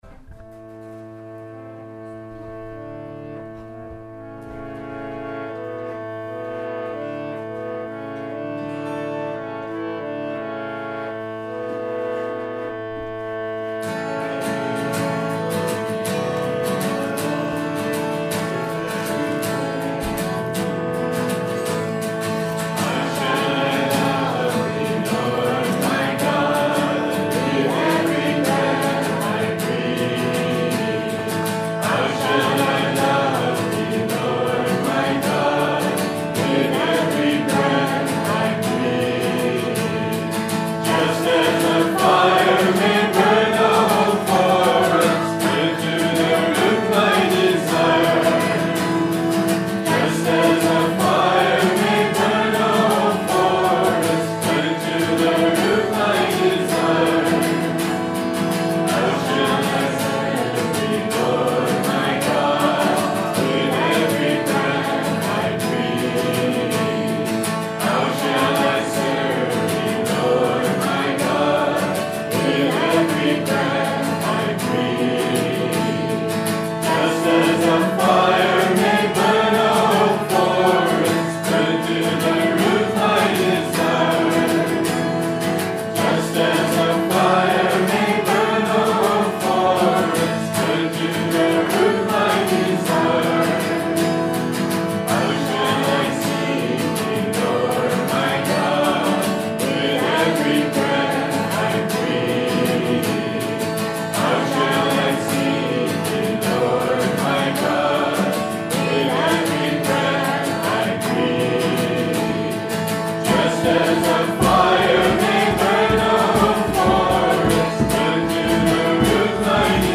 Talks By Other Ministers